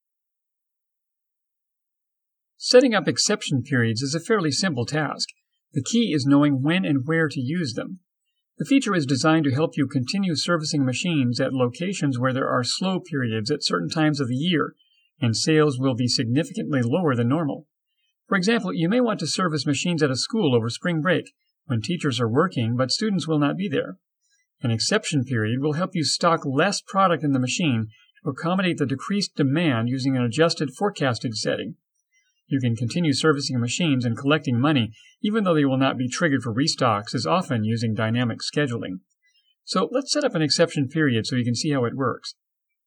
Mature Adult, Adult
Has Own Studio
My voice can be described as deep, smooth, fatherly, and kind, with an authoritative, story-teller vocal style.
E-Learning.mp3